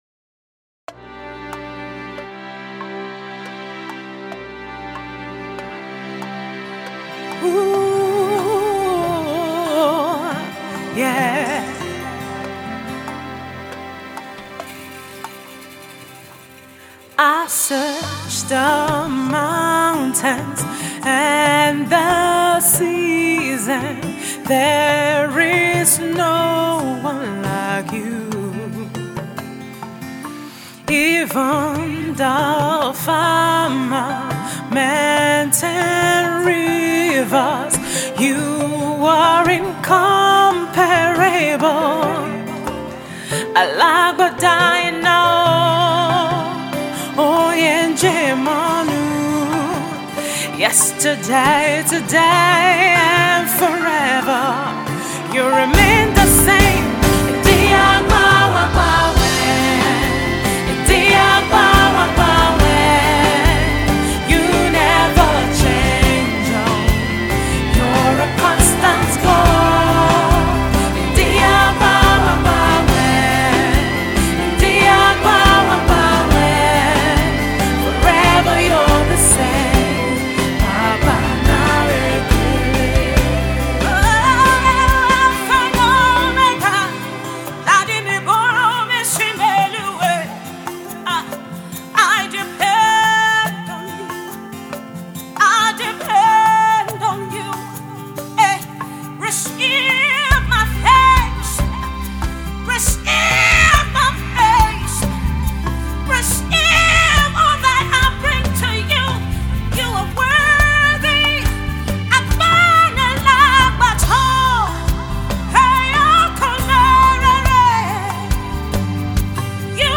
a simple worship song that is easy to learn